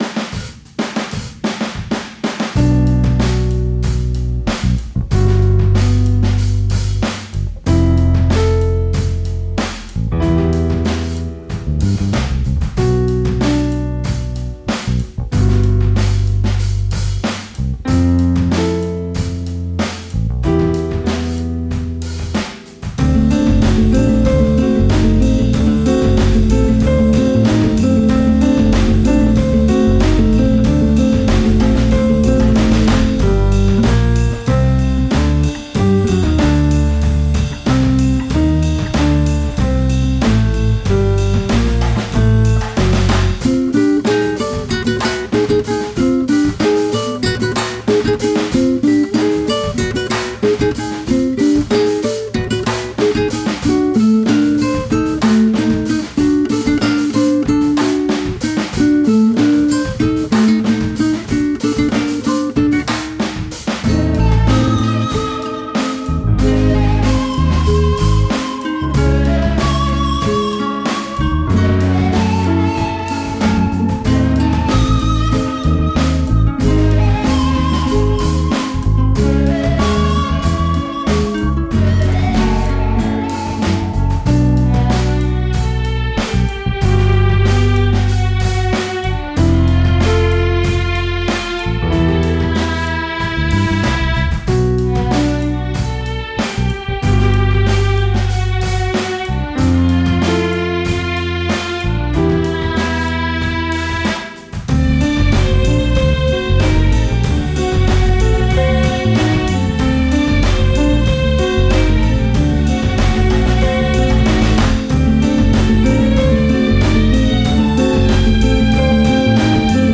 Exotic exploration music with Middle Eastern flavors.